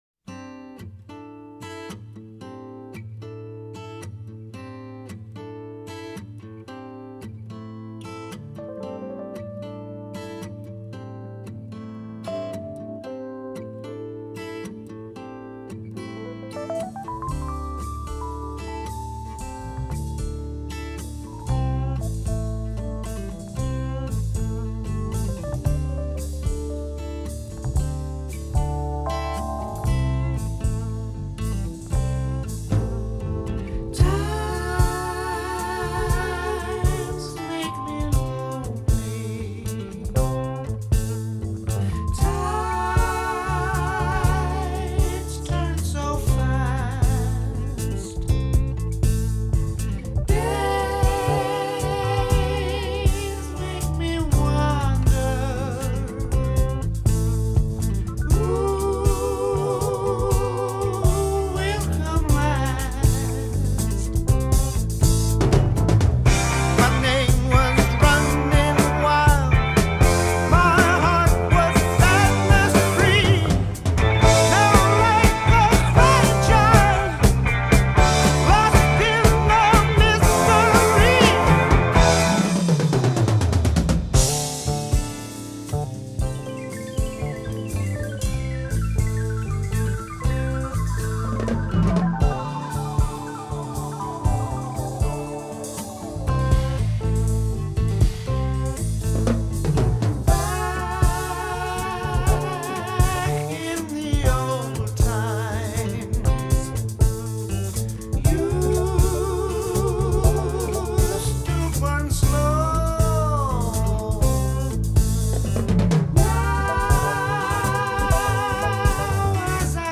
vocals, bass, harmonica
guitars, backing vocals
keyboards, vibraphone, backing vocals
drums, glockenspiel, backing vocals